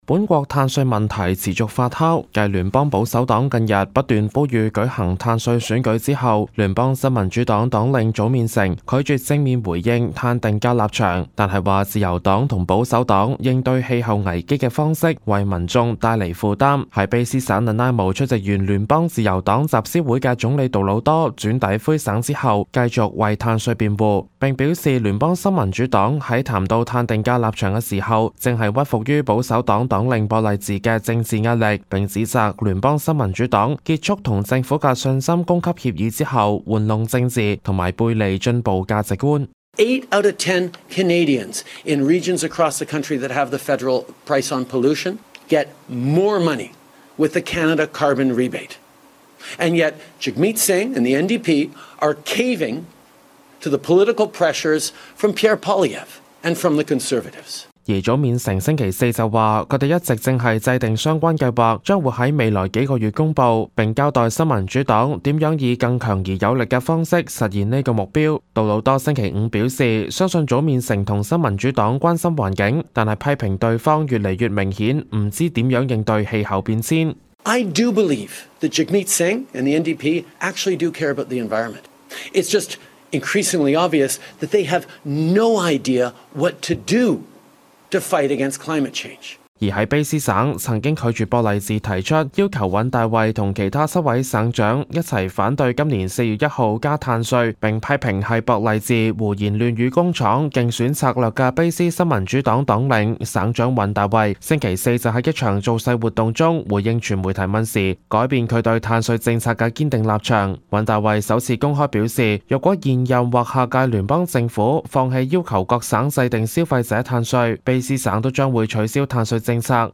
Canada/World News 全國/世界新聞
報道